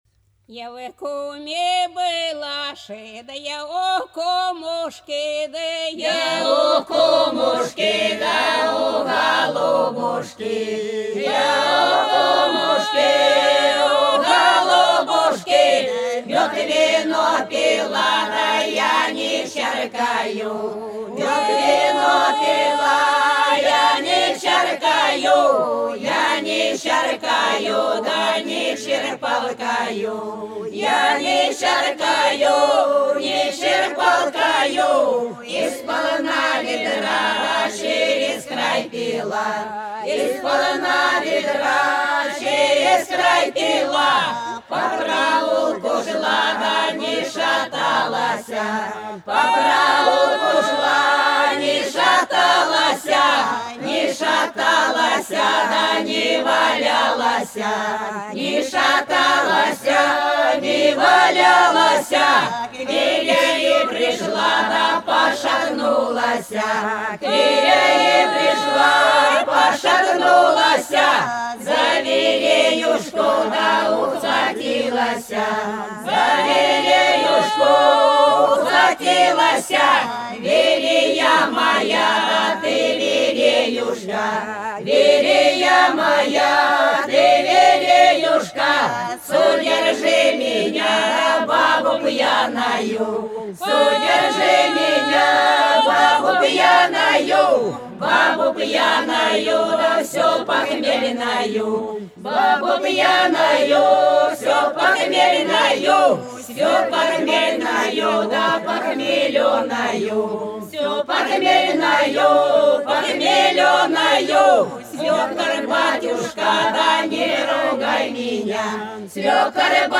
По-над садом, садом дорожка лежала Я в куме была - плясовая (с.Фощеватово, Белгородская область)
29_Я_в_куме_была_(плясовая).mp3